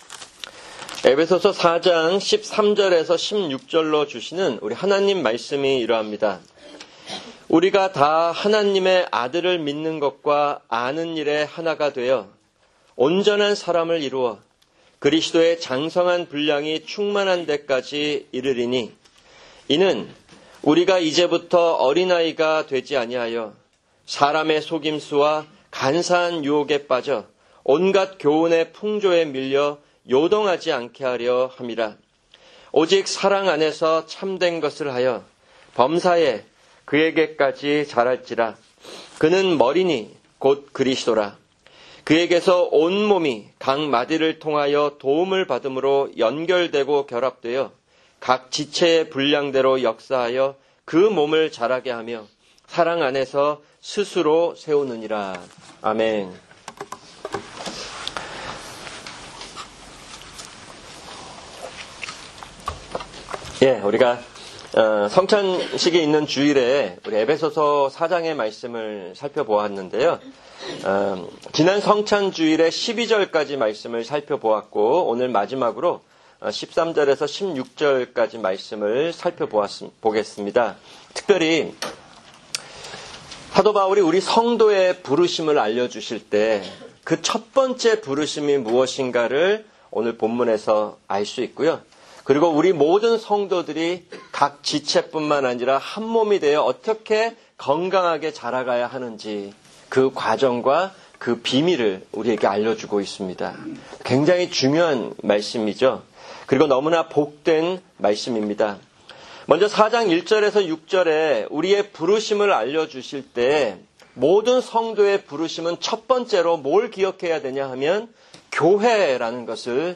[주일 설교] 에베소서4:13-16 – 성찬